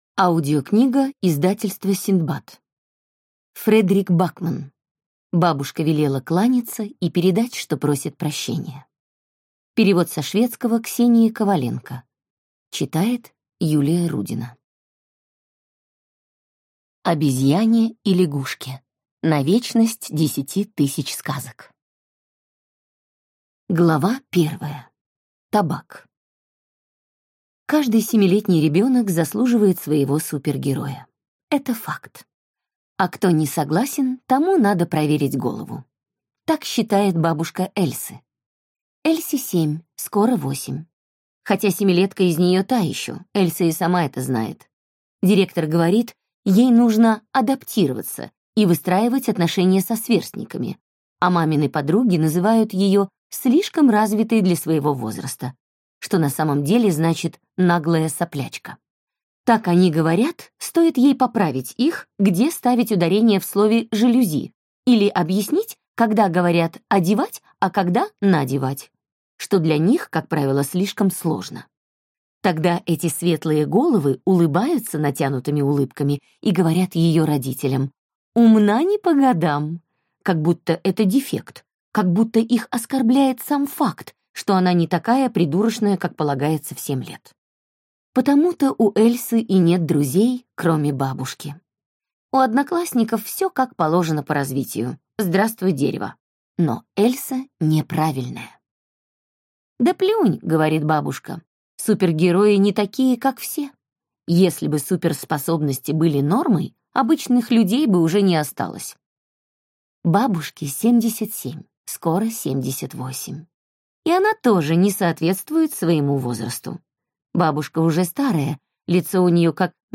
Аудиокнига Бабушка велела кланяться и передать, что просит прощения | Библиотека аудиокниг